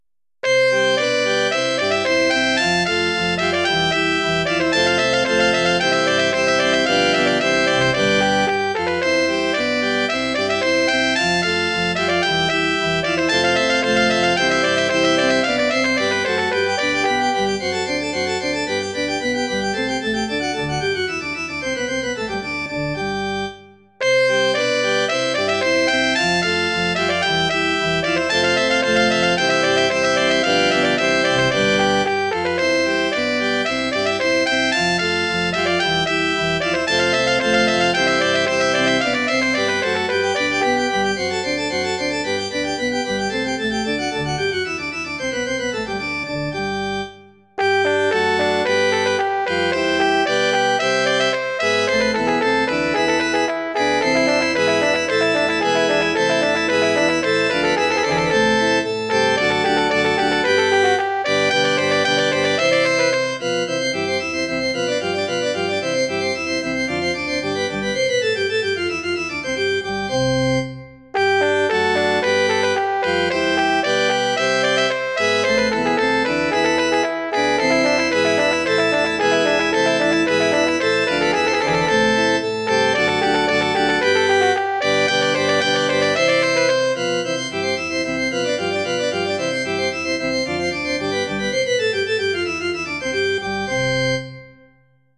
Bearbeitung für Trompete und Orgel in C-Dur oder D-Dur